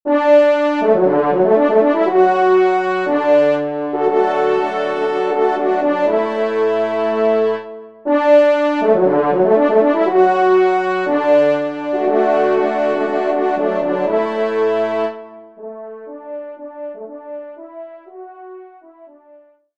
Genre :  Divertissement pour quatre Trompes ou Cors
ENSEMBLE